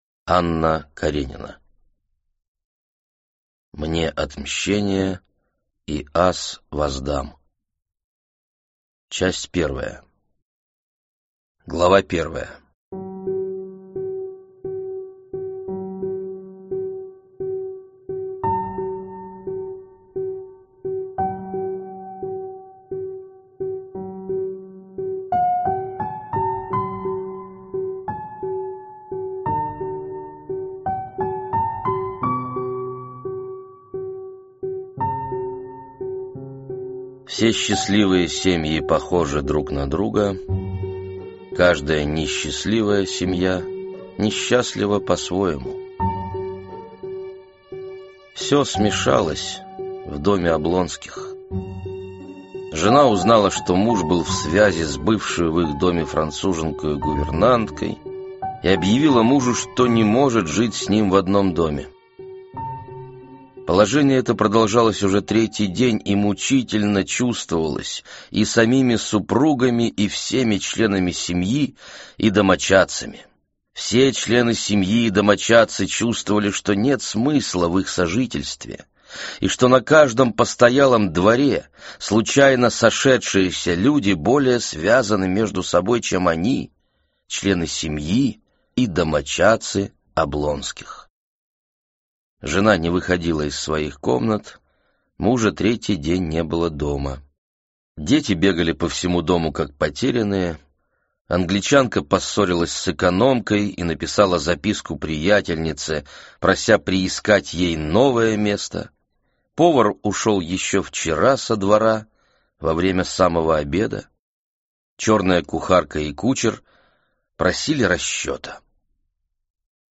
Аудиокнига Анна Каренина | Библиотека аудиокниг